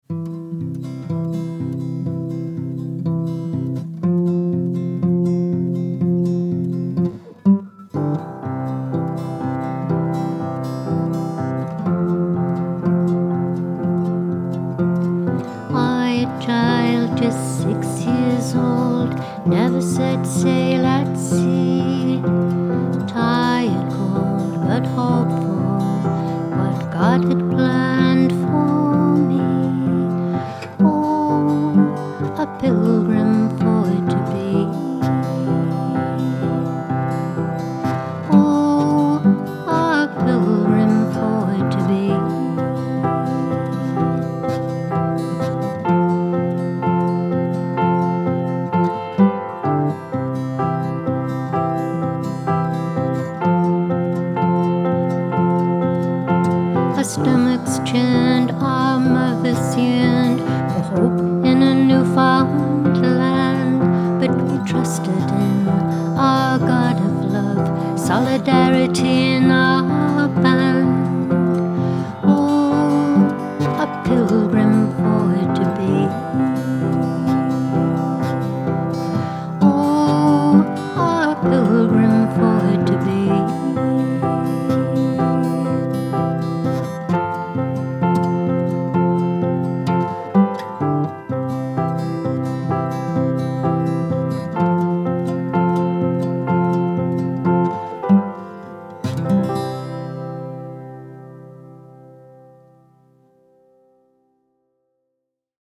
A-Pilgrim-For-To-Be-VOCAL-JT.mp3